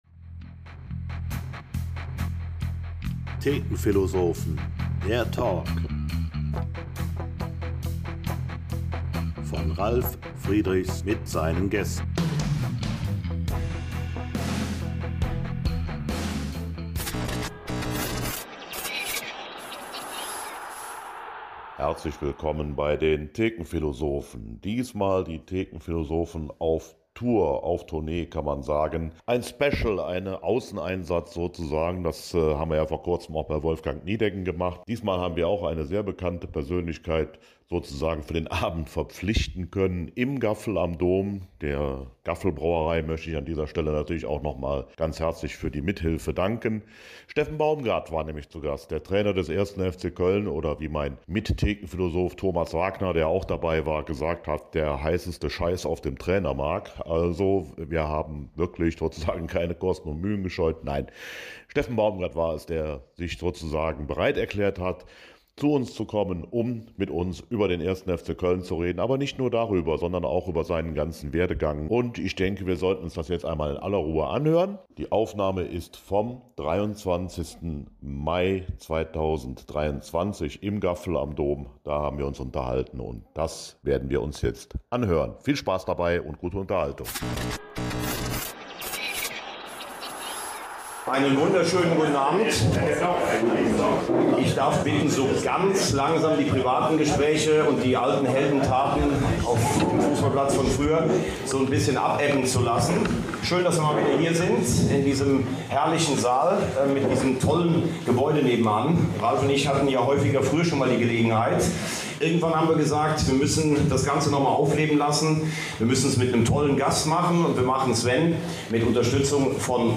Und wir waren nicht alleine, denn diesmal waren wir im Gaffel am Dom vor vollem Hause angetreten, um dieses besondere Gespräch zu führen.